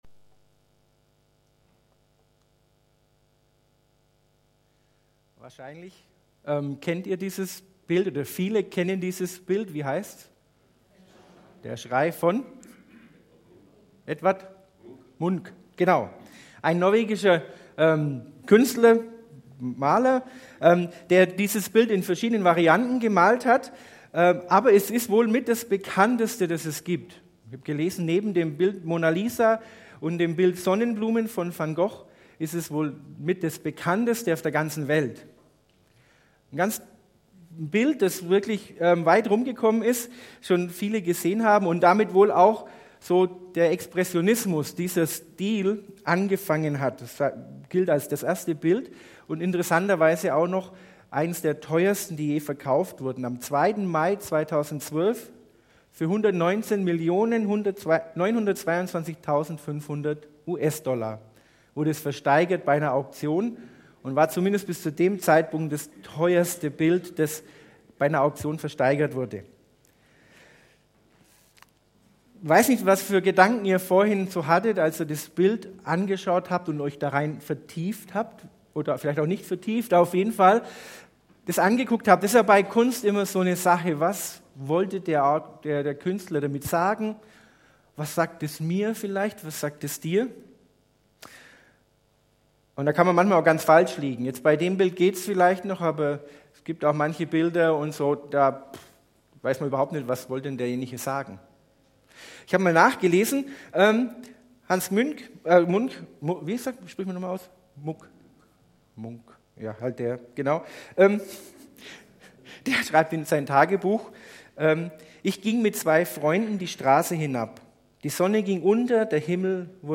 Predigt-Archiv - SV Langenau